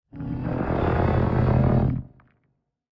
Minecraft Version Minecraft Version 25w18a Latest Release | Latest Snapshot 25w18a / assets / minecraft / sounds / mob / warden / ambient_1.ogg Compare With Compare With Latest Release | Latest Snapshot